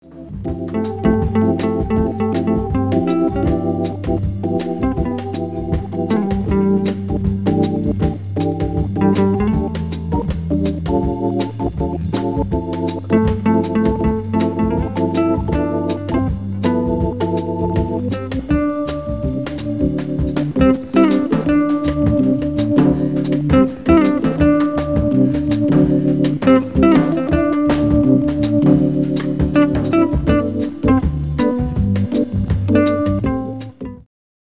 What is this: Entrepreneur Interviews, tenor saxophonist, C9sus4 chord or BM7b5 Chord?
tenor saxophonist